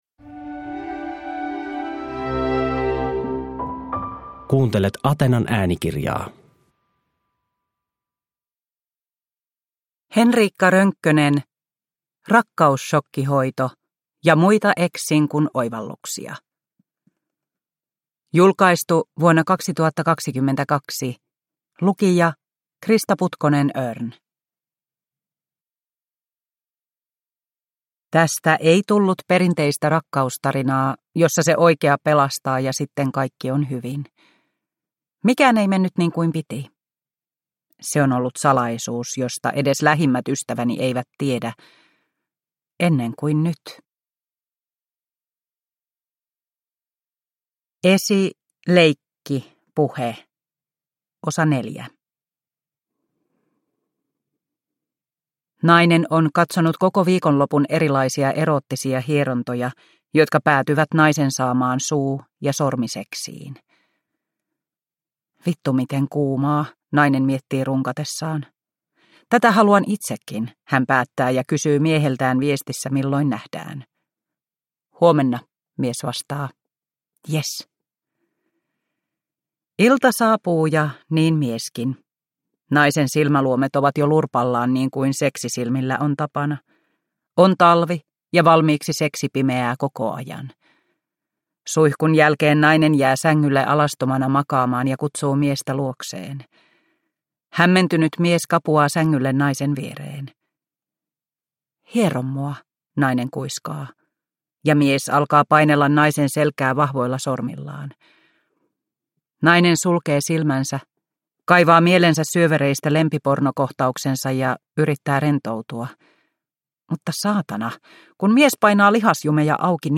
Rakkausshokkihoito – Ljudbok